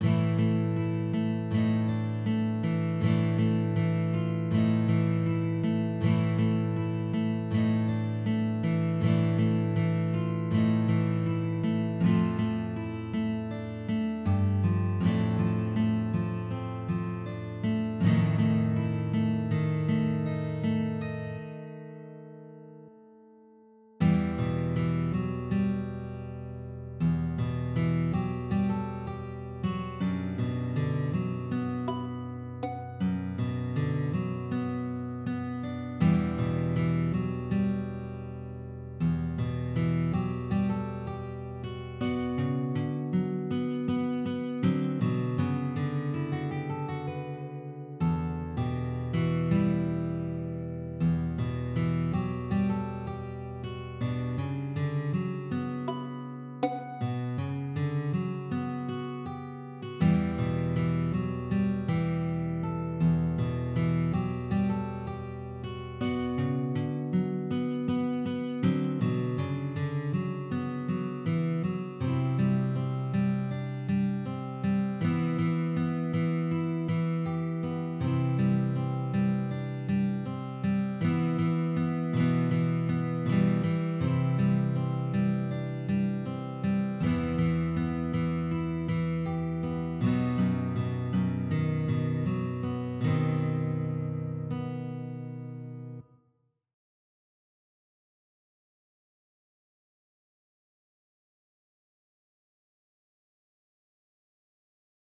на акустической гитаре